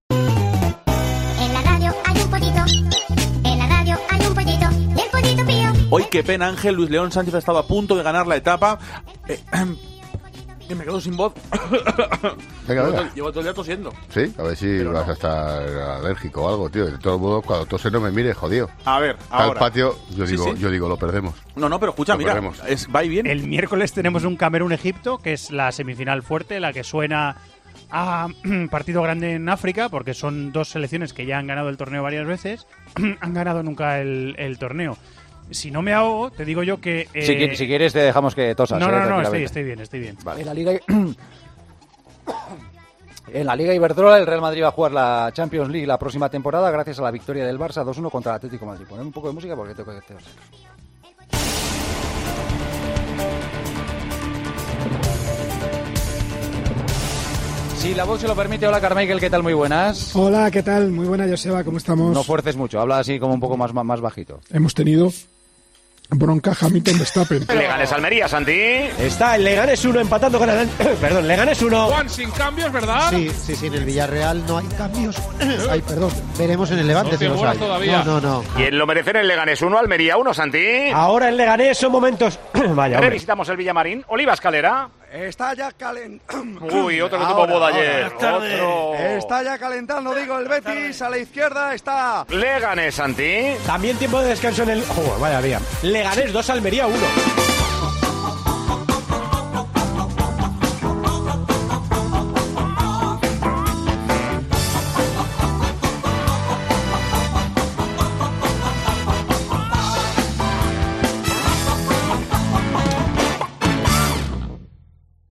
La labor del periodista no está exenta de posibles carrasperas, toses, estornudos... y eso pues también ha ocurrido a lo largo del año y en varias ocasiones.